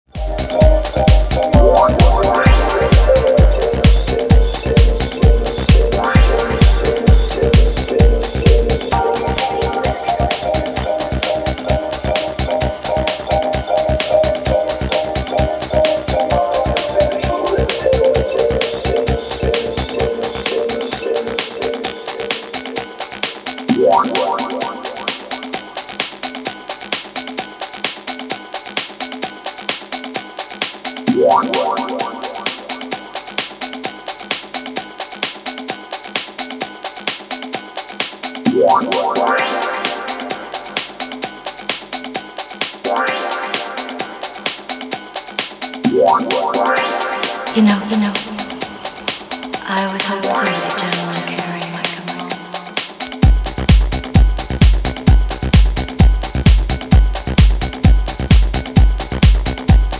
パーカッションとトランシーなリフが並走する柔らかなフロア・フィラー